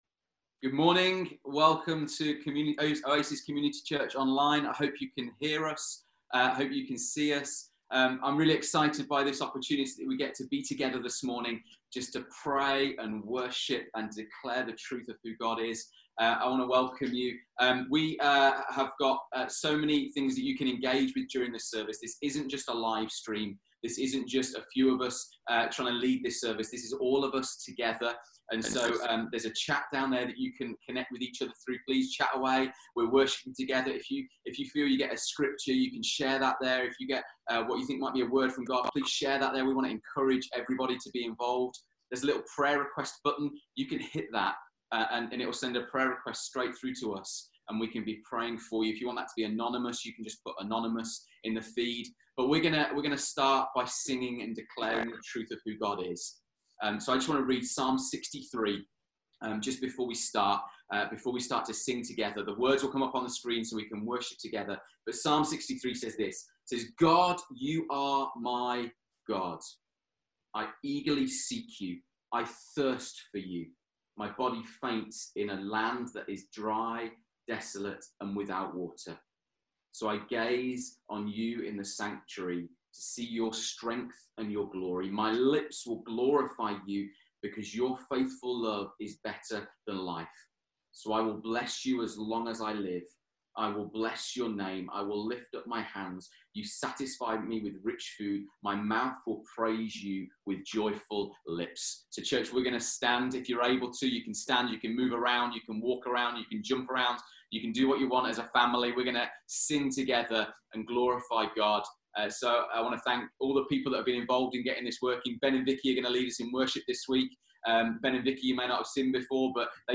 Listen again to our hour of prayer and worship from Sunday 29th March.